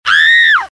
pain.mp3